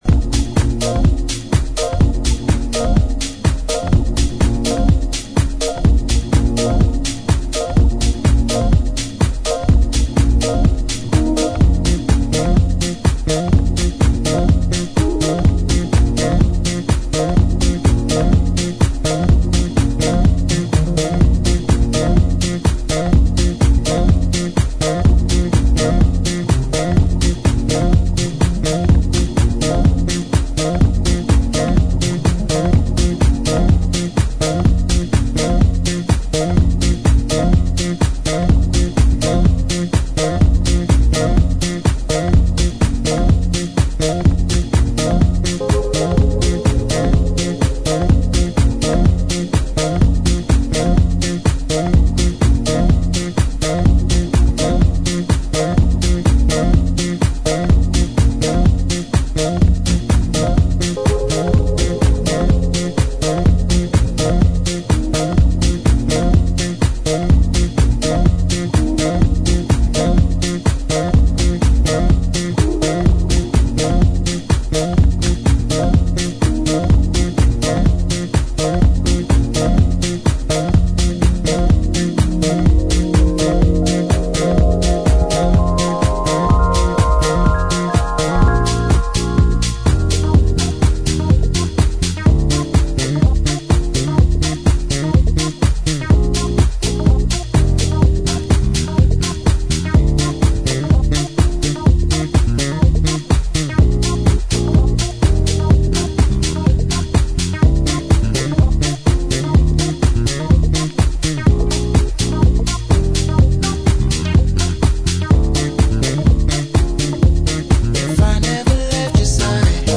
ファンキーなベース・ラインを効かせながら原曲を引き立てた温かい高揚感のハウスへと仕立てています。
ジャンル(スタイル) HOUSE / DEEP HOUSE